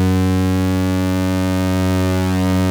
BUCHLA F#3#2.wav